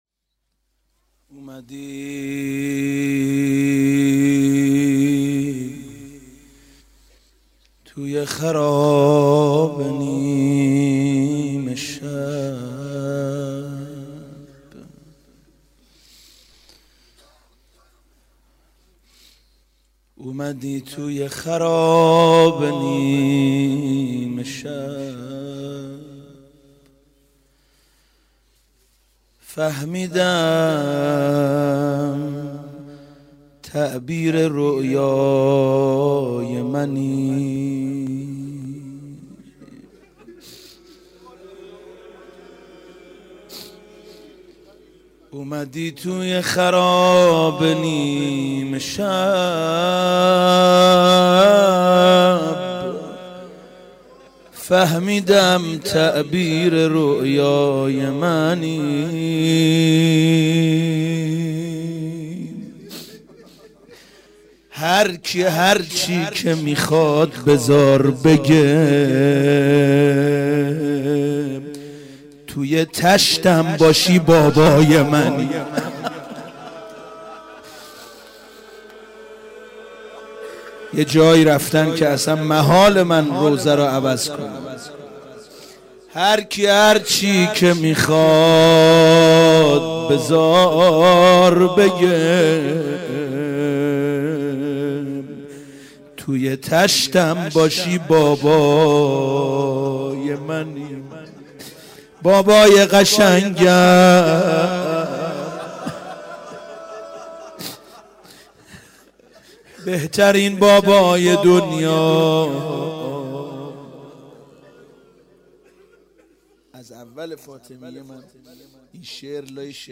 فاطمیه 96 - 20 بهمن - روضه - اومدی توی خرابه نیمه شب